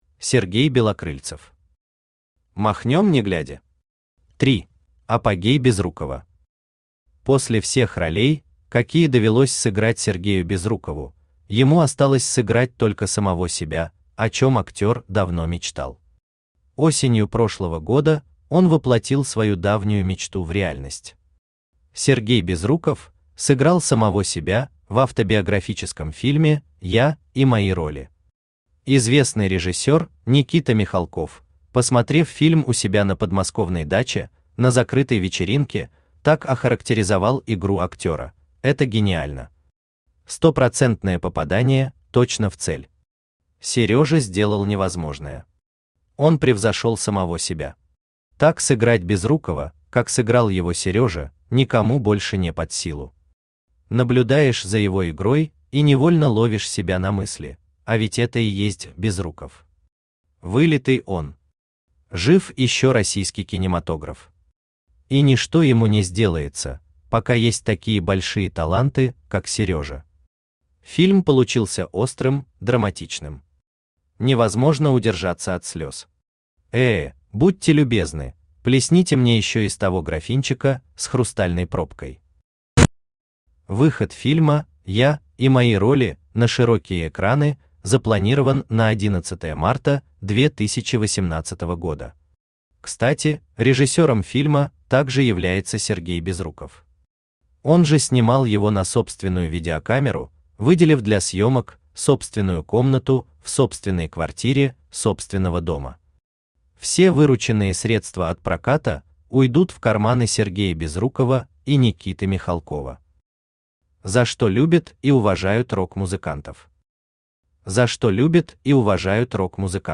– 3 Автор Сергей Валерьевич Белокрыльцев Читает аудиокнигу Авточтец ЛитРес.